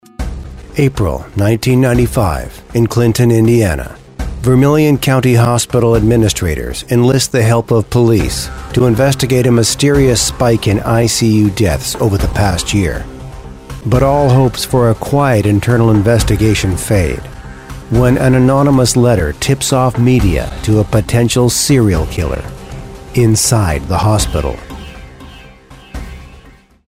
Narration Crime
Narration Crime.mp3